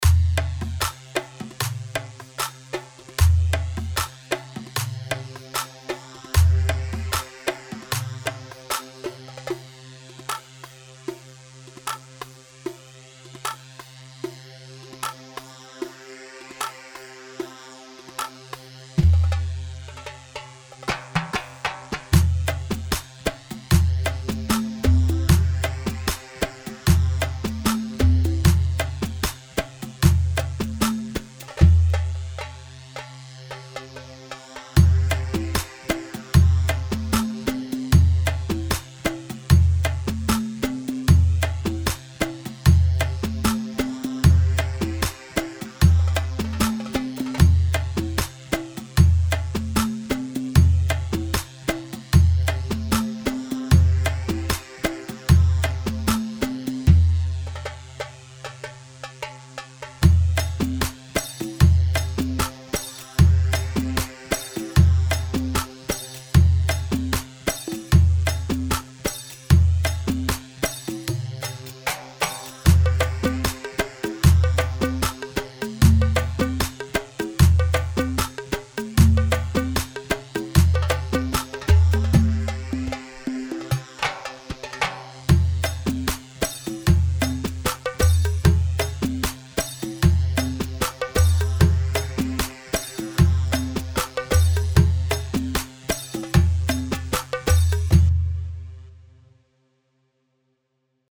Hewa 4/4 76 هيوا